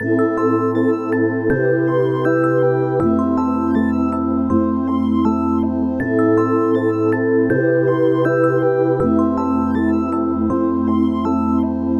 TO - Dro (160 BPM).wav